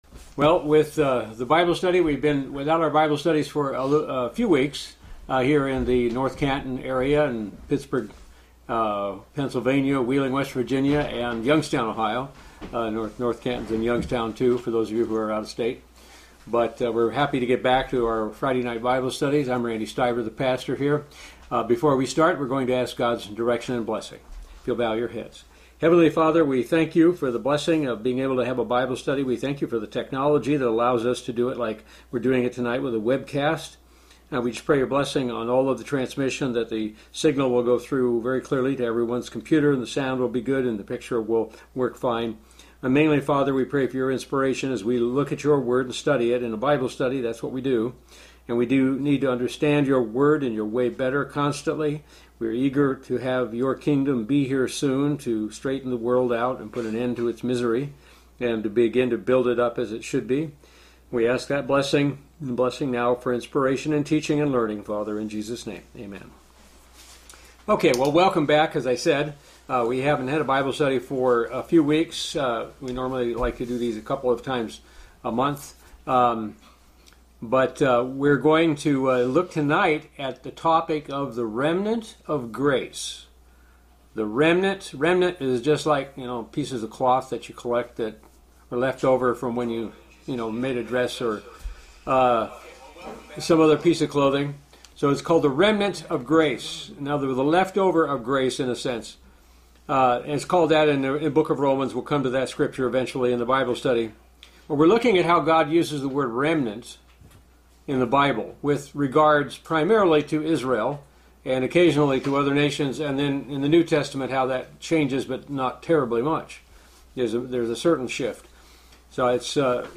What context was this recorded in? Given in North Canton, OH